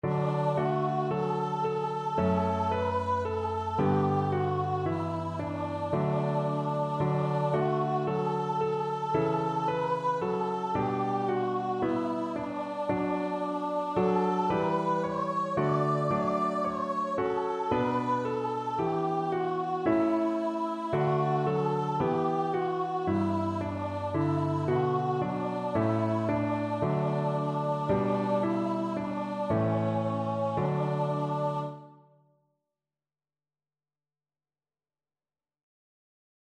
Free Sheet music for Voice
Traditional Music of unknown author.
4/4 (View more 4/4 Music)
Db5-D6
D major (Sounding Pitch) (View more D major Music for Voice )
Christian (View more Christian Voice Music)